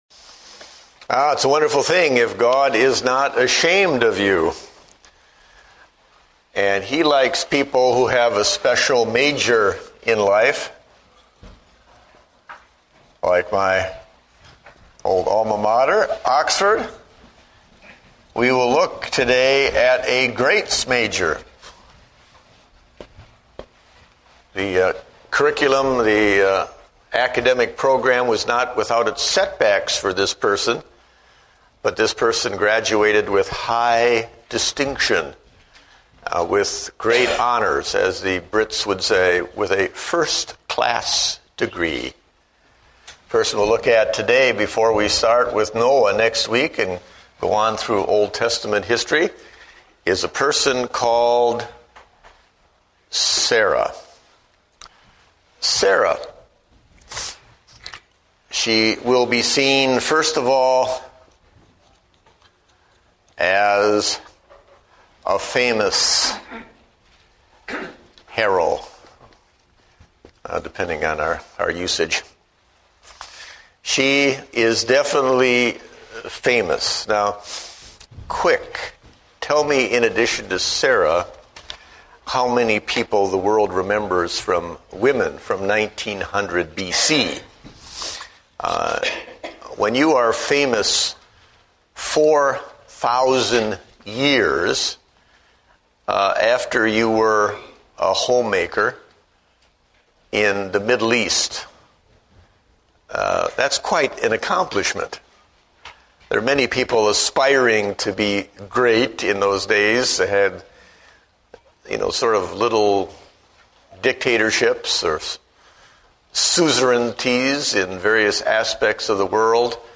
Date: January 11, 2009 (Adult Sunday School)